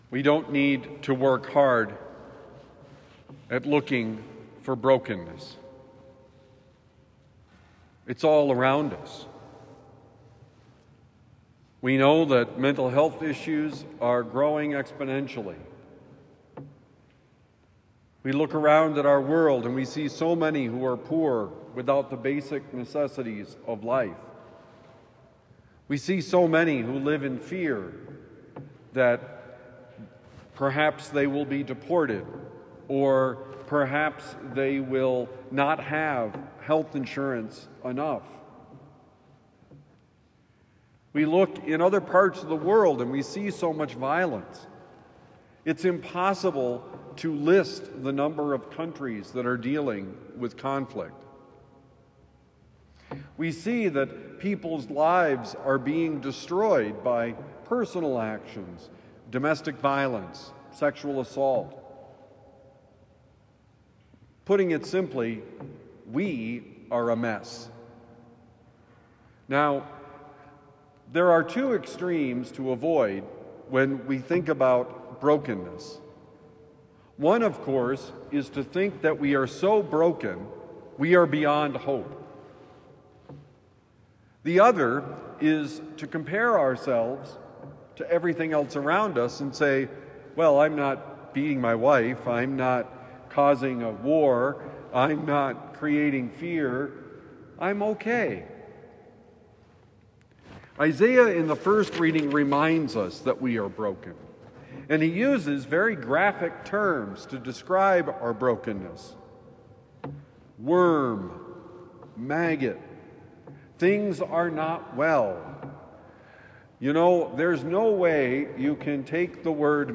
Not an endearing term: Homily for Thursday, December 11, 2025